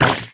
Minecraft Version Minecraft Version 1.21.5 Latest Release | Latest Snapshot 1.21.5 / assets / minecraft / sounds / entity / squid / hurt4.ogg Compare With Compare With Latest Release | Latest Snapshot
hurt4.ogg